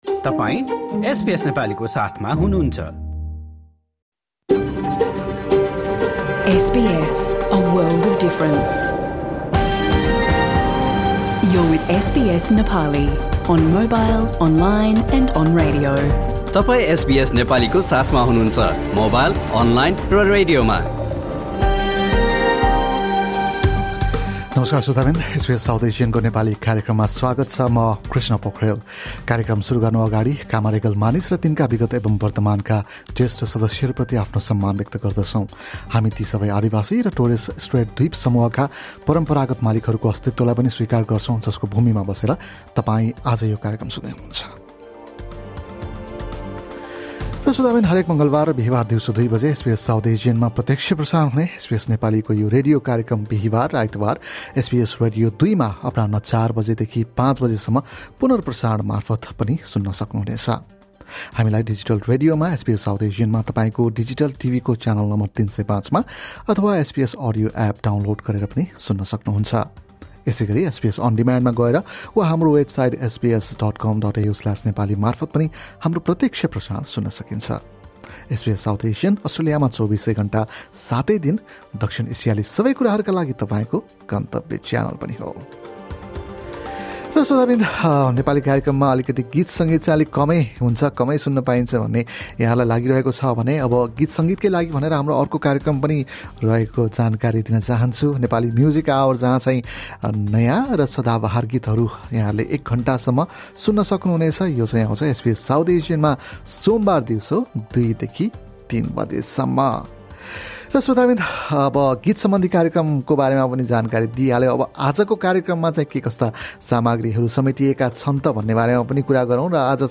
SBS Nepali broadcasts a radio program every Tuesday and Thursday at 2 PM on SBS South Asian digital radio and channel 305 on your TV, live from our studios in Sydney and Melbourne.